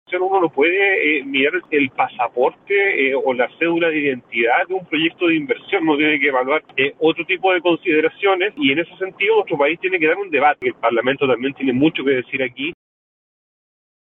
Por su parte, desde el Frente Amplio, el diputado Jaime Sáez advirtió que una decisión de esta magnitud no puede supeditarse a la continuidad de la Visa Waiver y recalcó que el Congreso debe tener un rol activo antes de una definición final.